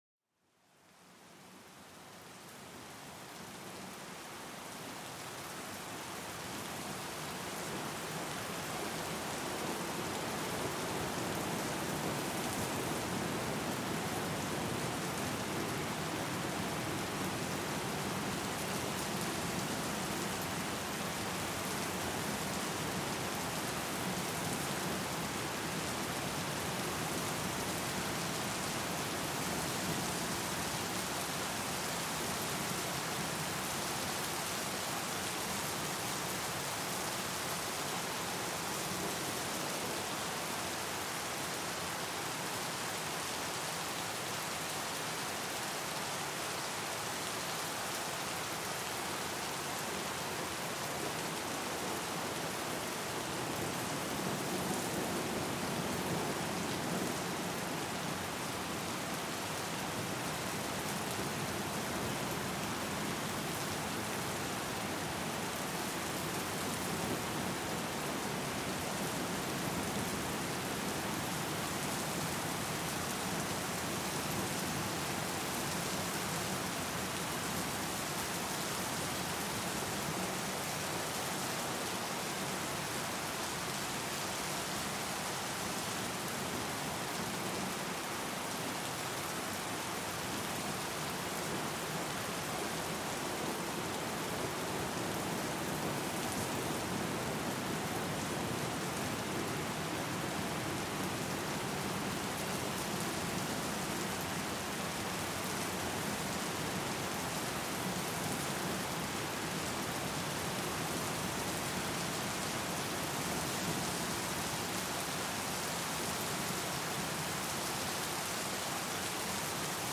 Lluvia torrencial para calmar la mente